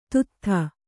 ♪ tuttha